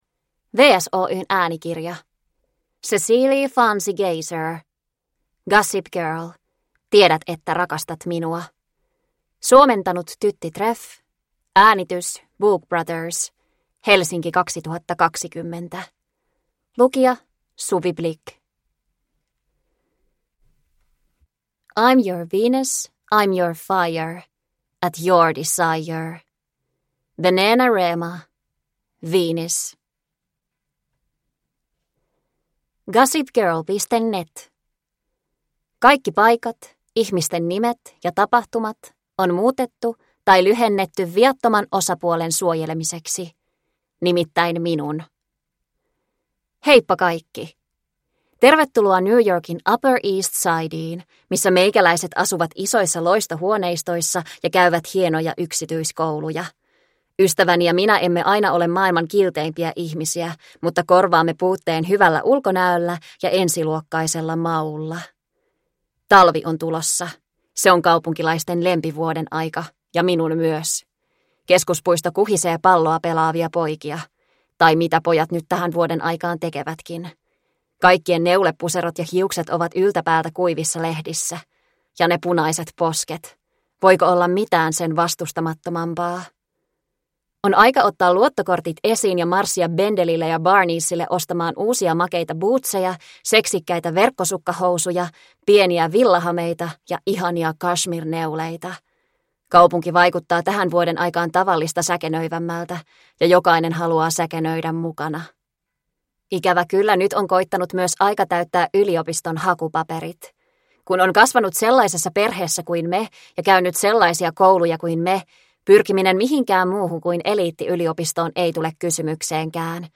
Tiedät että rakastat minua – Ljudbok – Laddas ner